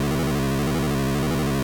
The sound Andor Genesis makes in Namco Roulette in SSB4